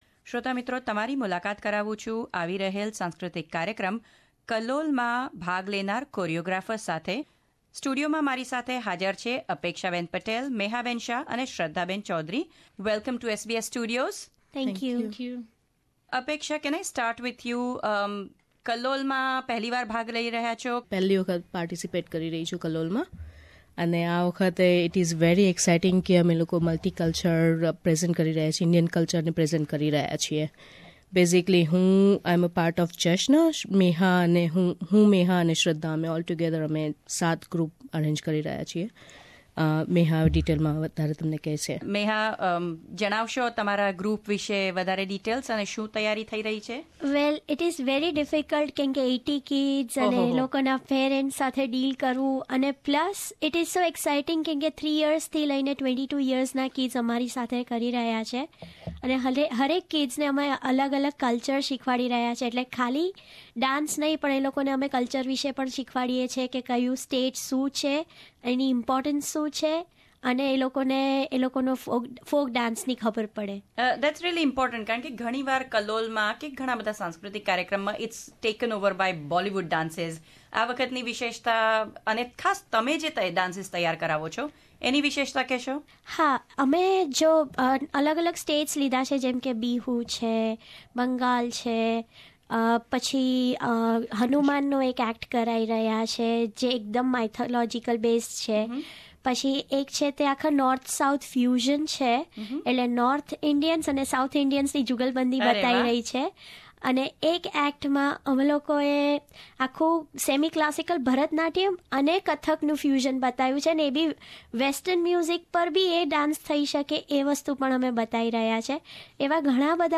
Young performers and choreographers from Kallol 2016 visited SBS Studios to give us a preview of what to expect at this year's cultural event organised by Gujarati Samaj of NSW.